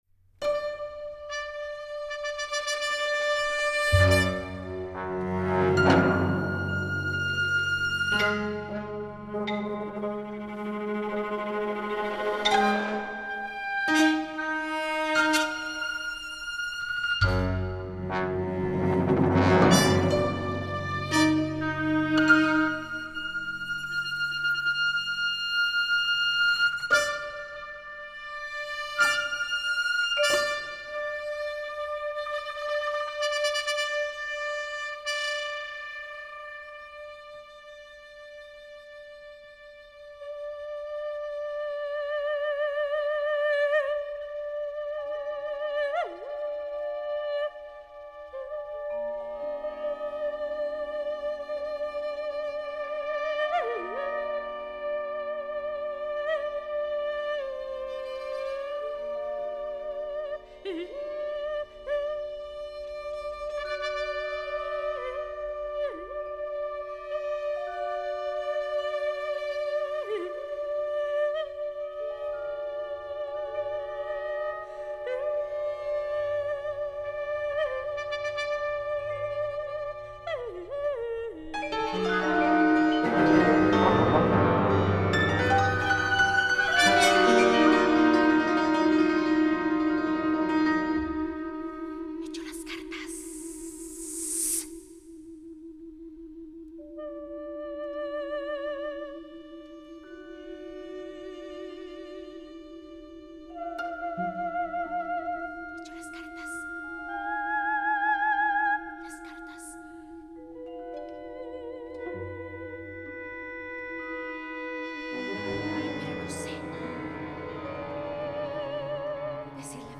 In Verso para voz y catorce instrumentistas